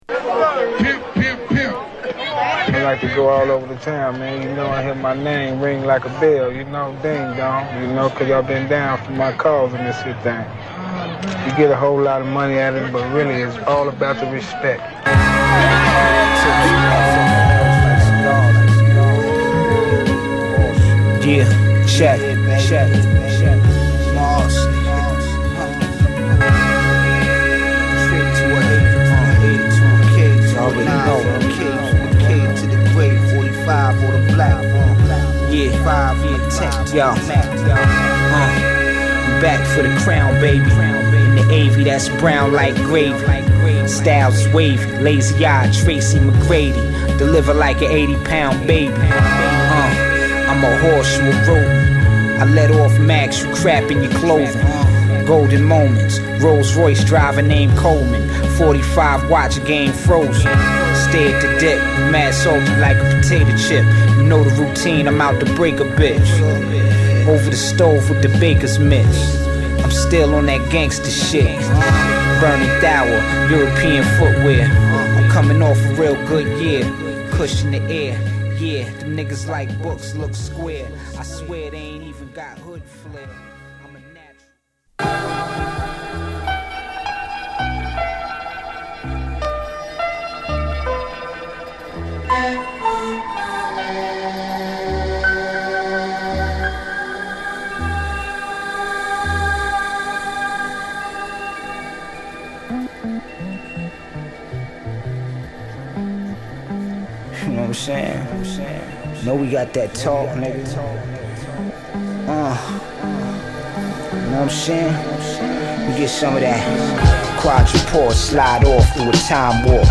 2LP)Hip Hop / R&B